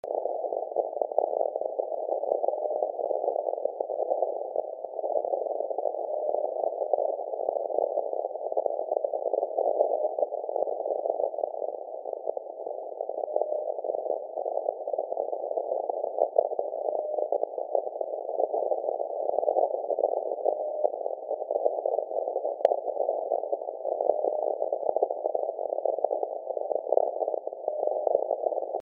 [Lowfer] alpha beeps tonight
Good propagation and/or low noise condx tonight ... alpha beeps clearly